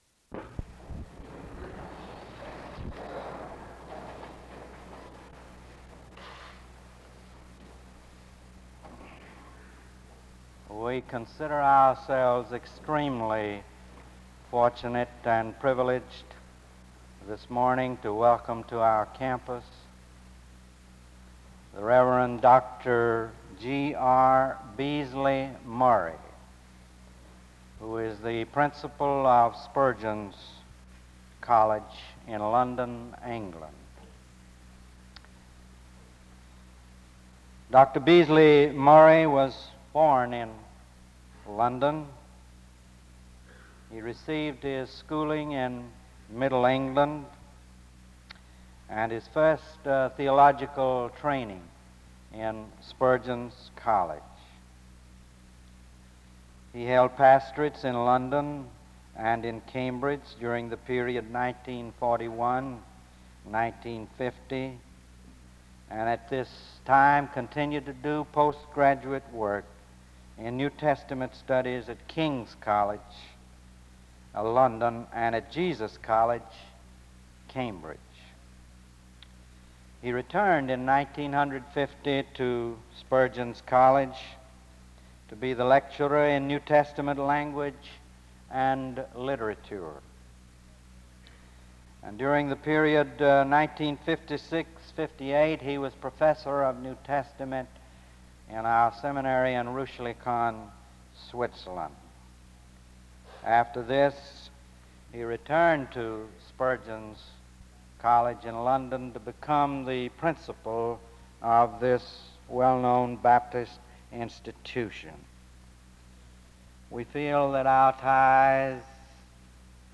After an introduction (start-2:55), Dr. George Raymond Beasley-Murray, the Principal of Spurgeon’s College in London, gives his first of three lectures on the subject of Baptism.